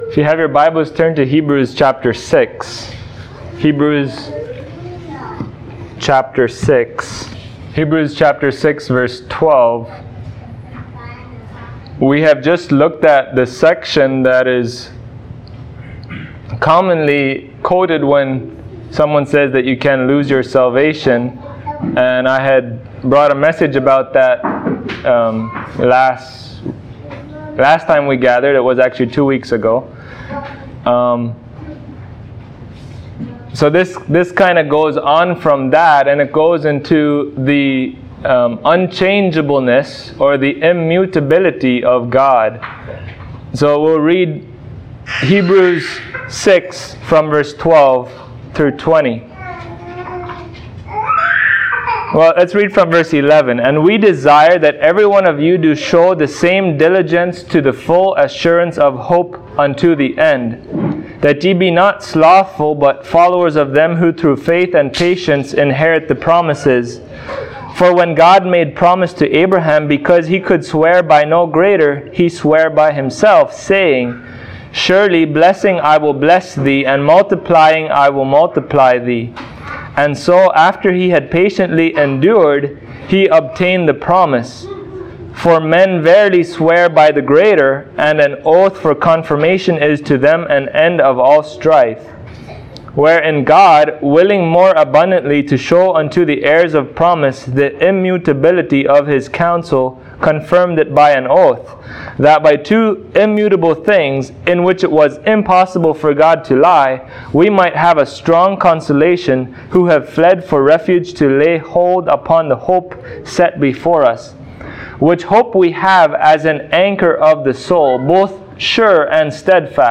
Hebrews 6:13-20 Service Type: Sunday Morning What does it mean that God is immutable?